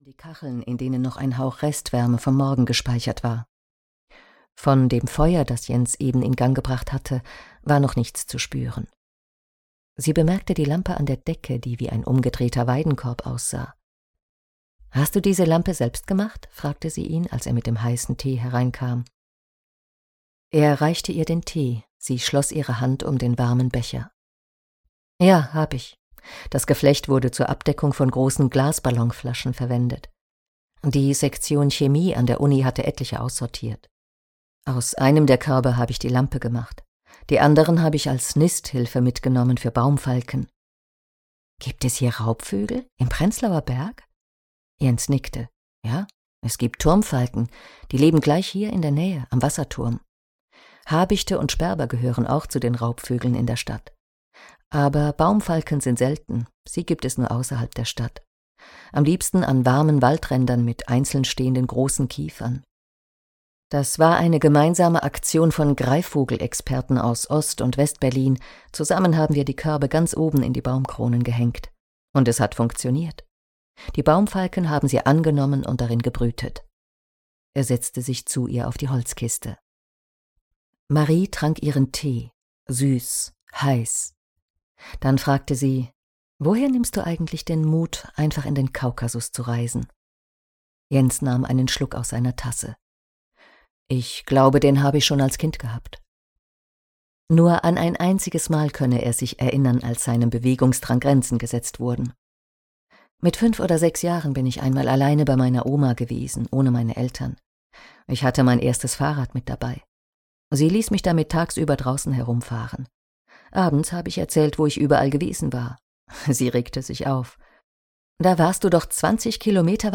Die verbotene Reise - Peter Wensierski - Hörbuch